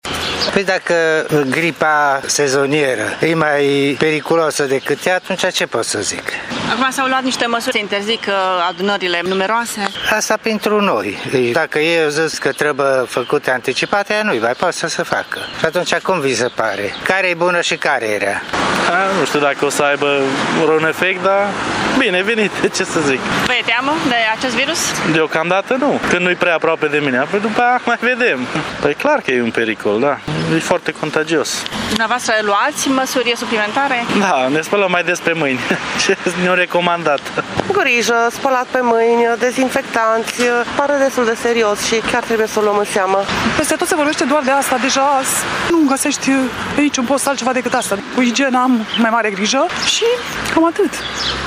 Târgumureșenii urmăresc atent evoluția bolii și respectă recomandările specialiștilor: